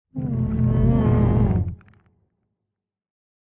Minecraft Version Minecraft Version snapshot Latest Release | Latest Snapshot snapshot / assets / minecraft / sounds / mob / warden / ambient_11.ogg Compare With Compare With Latest Release | Latest Snapshot